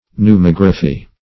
Search Result for " pneumography" : The Collaborative International Dictionary of English v.0.48: Pneumography \Pneu*mog"ra*phy\, n. [Pneumo- + -graphy.]